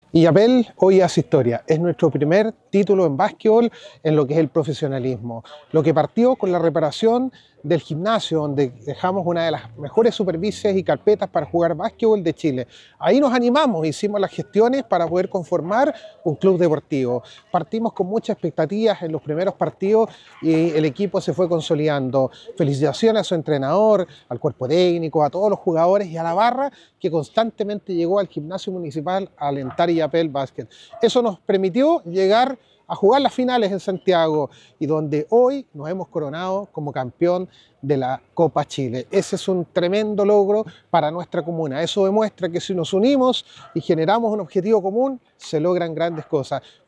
Cuna-Alcalde-por-Illapel-Basket.mp3